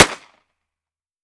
38 SPL Revolver - Gunshot A 003.wav